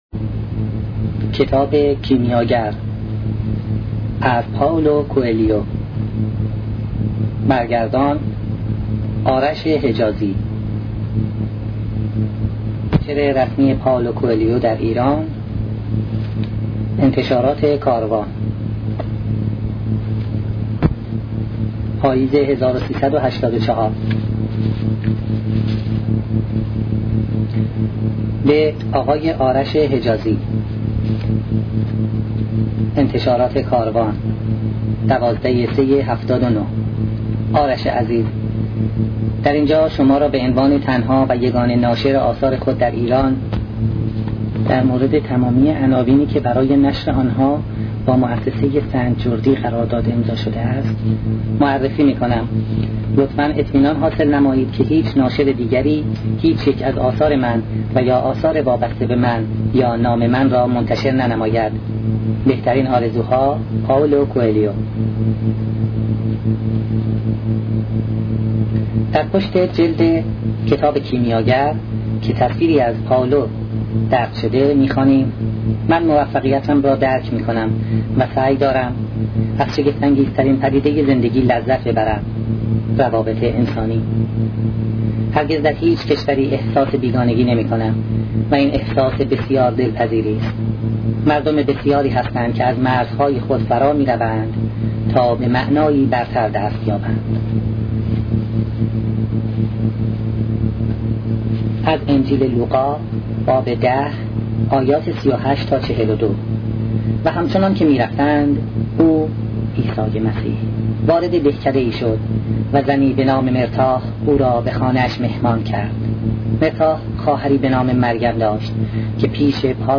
کتاب صوتی کیمیاگر